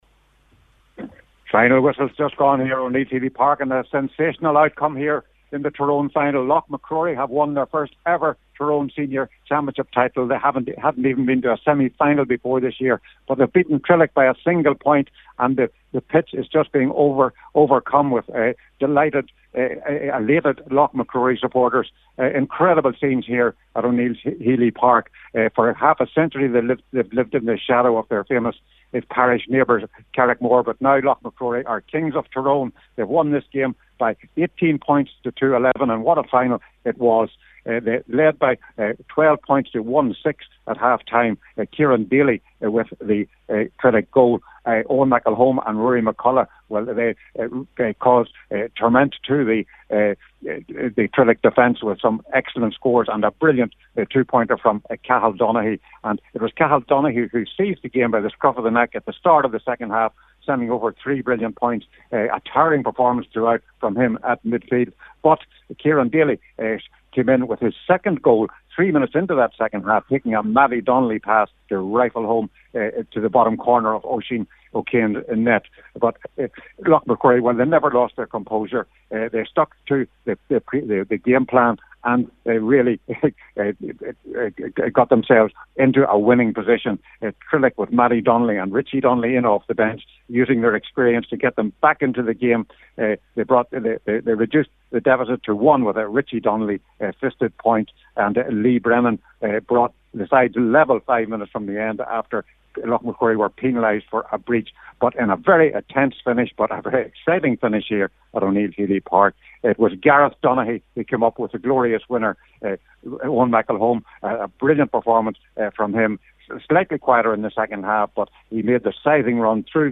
the full time report…